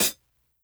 Medicated Hat 8.wav